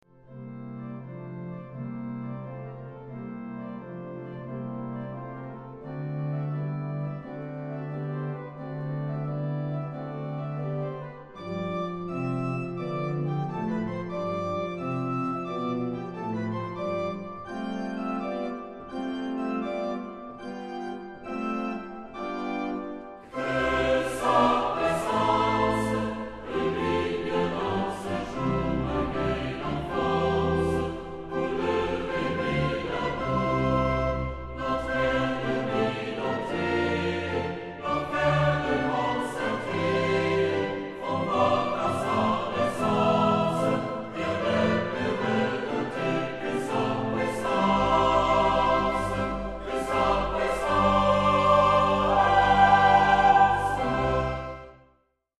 Charakter des Stückes: allegretto
Chorgattung: SATB  (4 gemischter Chor Stimmen )
Tonart(en): C-Dur